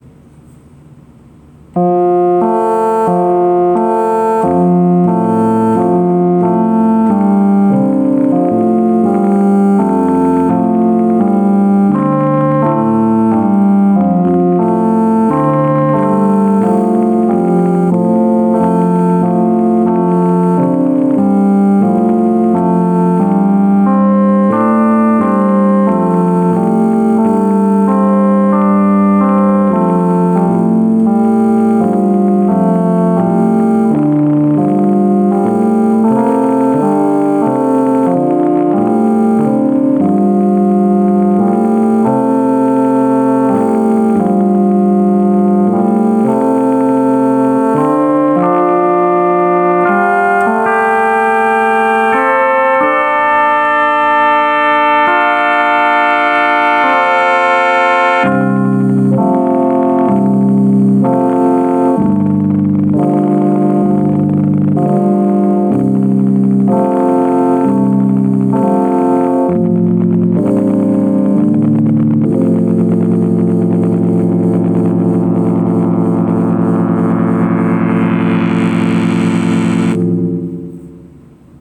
playing their hearts out on the casio keyboard